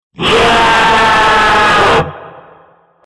Sound Effect: Demonic scream echo - The AI Voice Generator
Listen to the AI generated sound effect for the prompt: "Demonic scream echo".